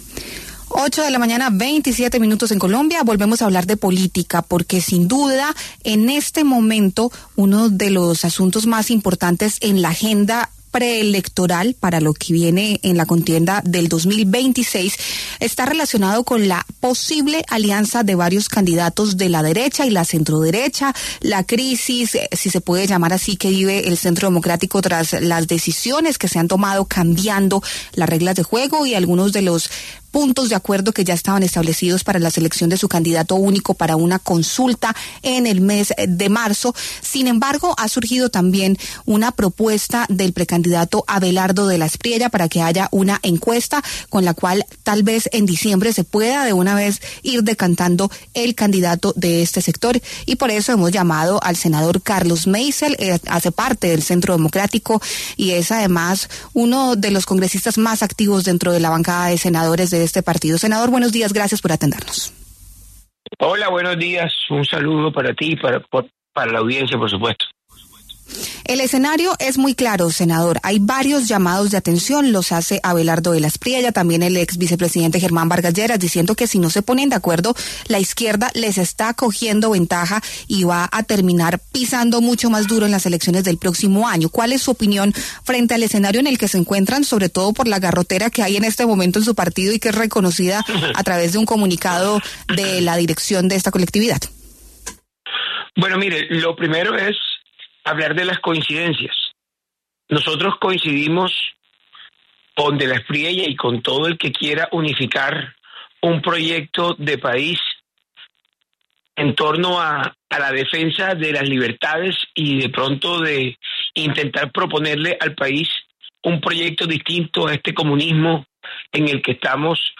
Carlos Meisel, senador del Centro Democrático, se refirió en los micrófonos de La W a una alianza de la derecha en el país y envió un mensaje contundente para unificar el sector de cara a las elecciones presidenciales de 2026.